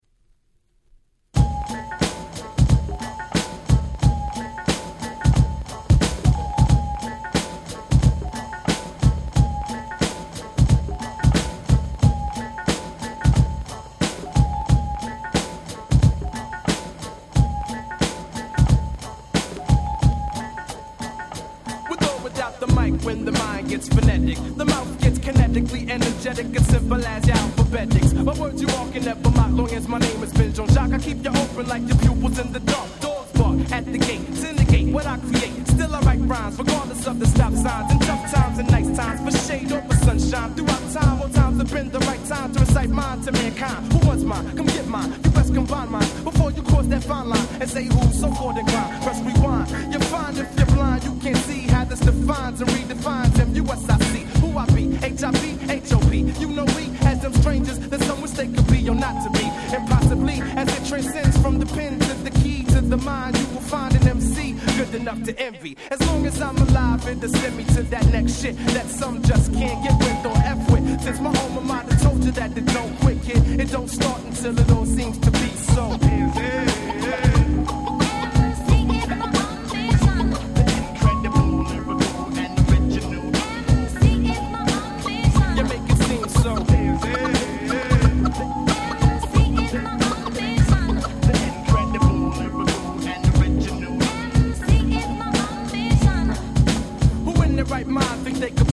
95' Smash Hit Underground Hip Hop !!